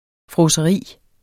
Udtale [ fʁɔːsʌˈʁiˀ ]